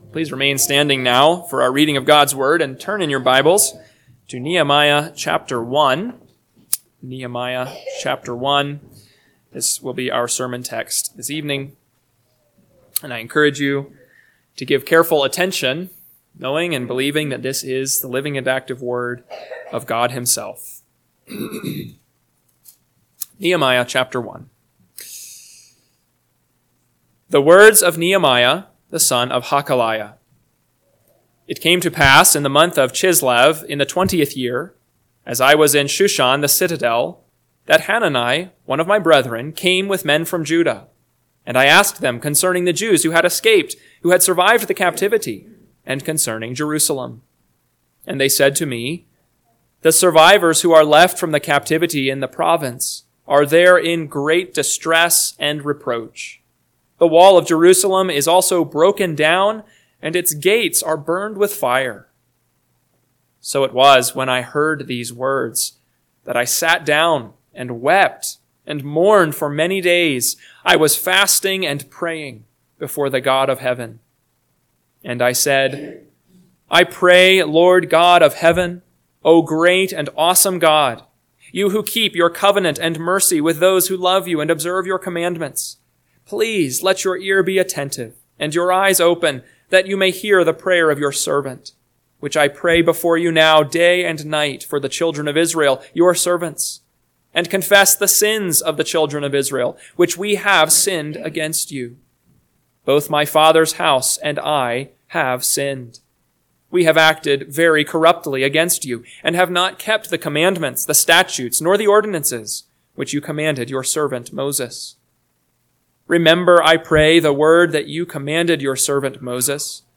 PM Sermon – 6/8/2025 – Nehemiah 1 – Northwoods Sermons